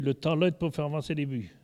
Il chante pour faire avancer les bœufs
Catégorie Locution